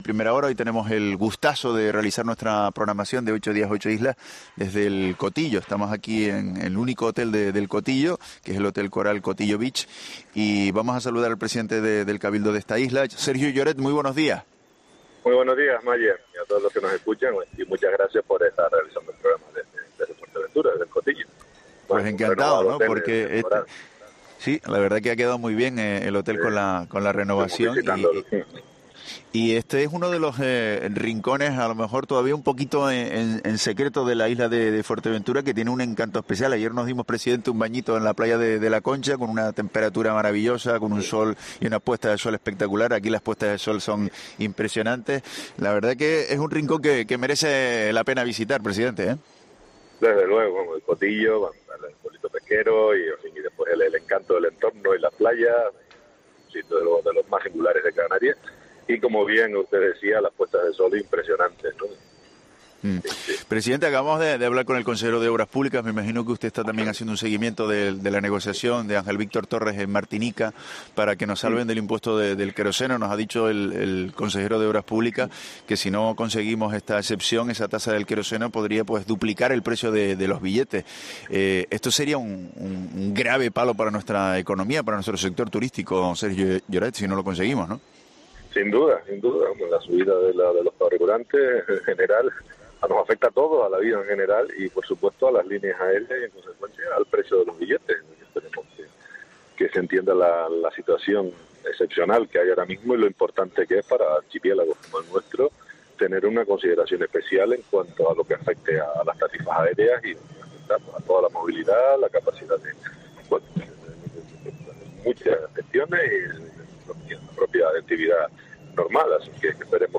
Sergio Lloret, presidente del Cabildo de Fuerteventura